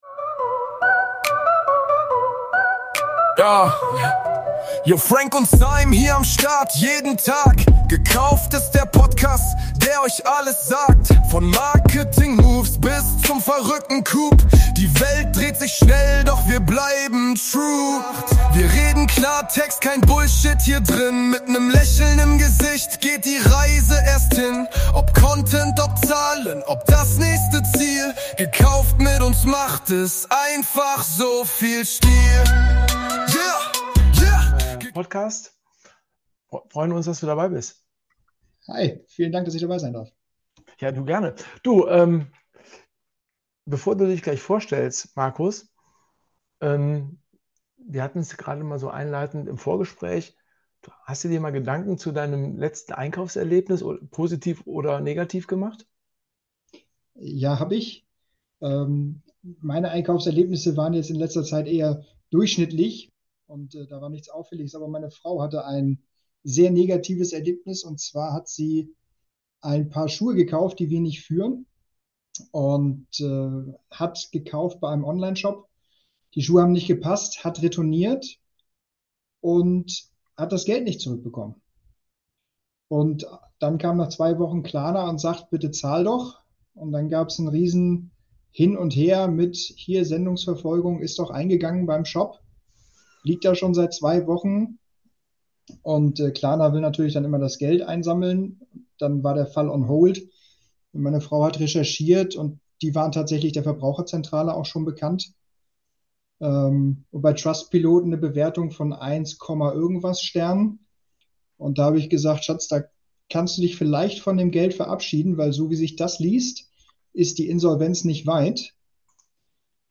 Freut euch auf ehrliche Einblicke, echte Praxis und eine offene Diskussion darüber, wie man als Mittelständler technologisch vorangeht, ohne den Kern des stationären Geschäfts zu verlieren.